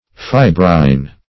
Fibrine \Fi"brine\, a.